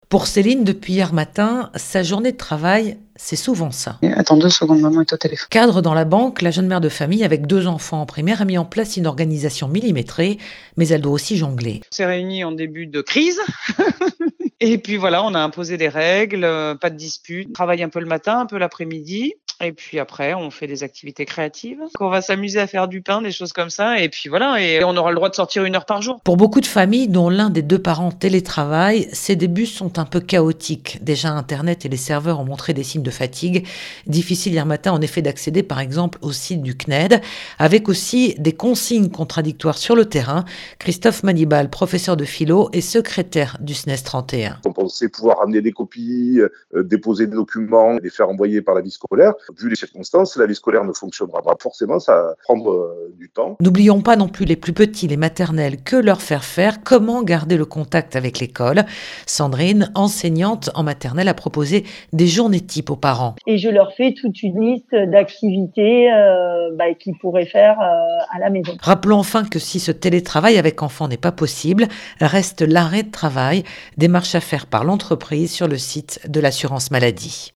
Reportage en télétravail